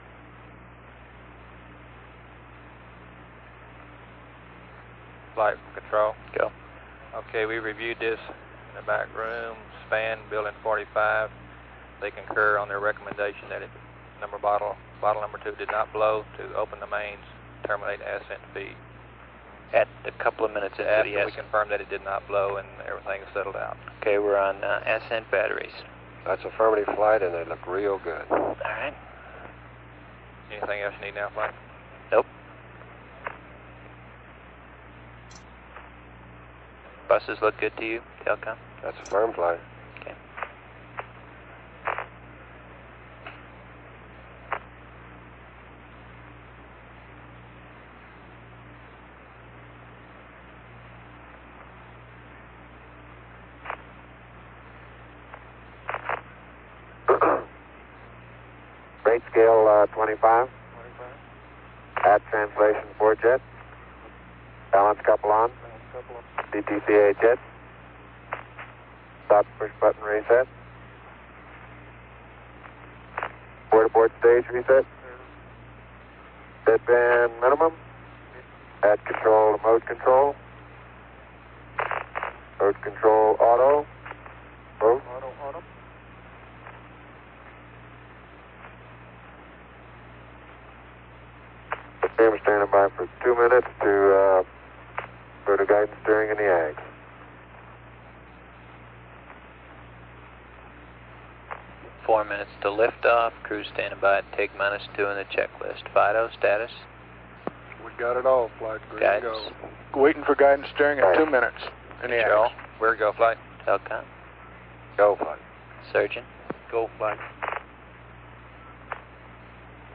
Flight Director’s loop 20 minutes / 7.3MB – mono mp3 file This recording from the Flight Director’s loop in the MOCR gives a good feel for the ‘tip of the iceberg’ that was the MOCR. There is a great deal of activity from various support teams behind the scenes. The Black Team is on duty – Glynn Lunney is the Flight Director at this point.
A11_lunar_ascent_FD_loop.mp3